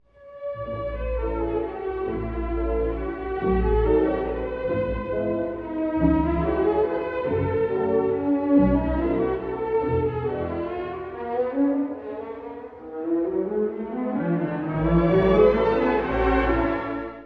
(序奏) 古い音源なので聴きづらいかもしれません！
通例はスケルツォやメヌエットですが、本作はワルツで置き換えられています。
まるで夢のなかを遊ぶよう